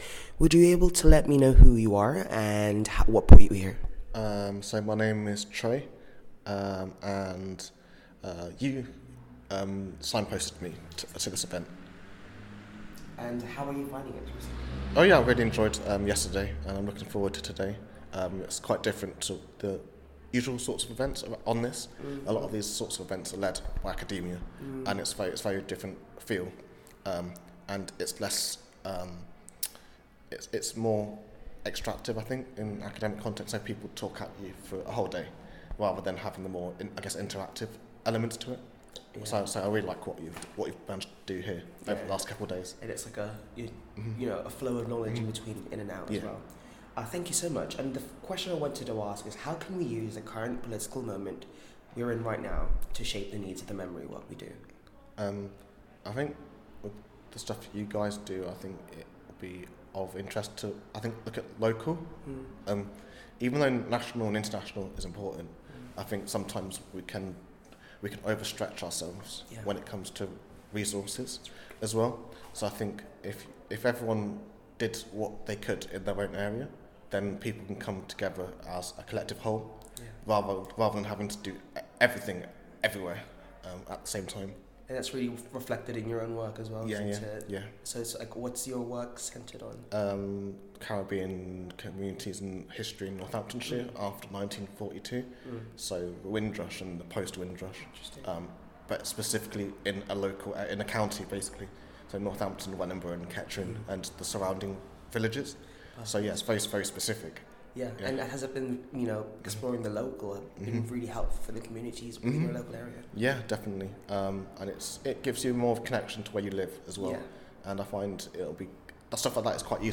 The following audio reflections, recorded during the Brighton convening, feature members of the UK Community of Practice addressing the urgent question: How can we use the current political climate we’re in right now to shape the needs of memory work?